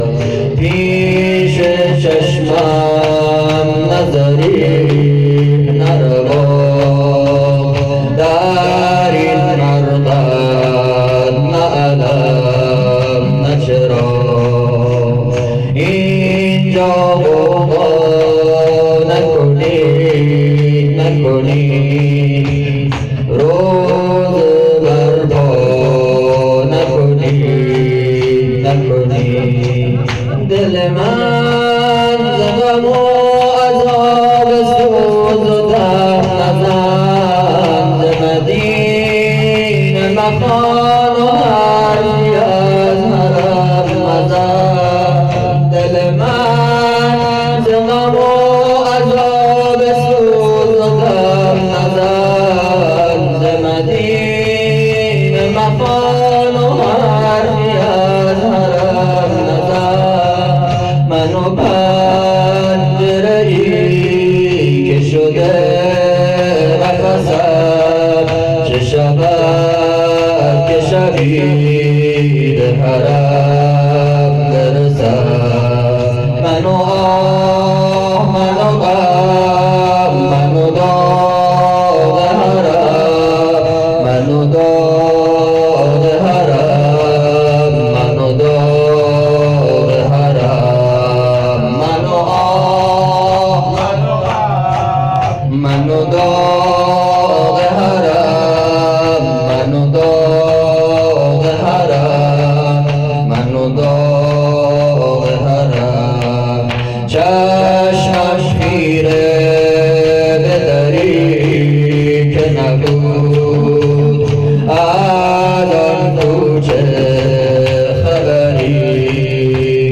بزرگداشت ائمه بقیع(ع)
شور احساسی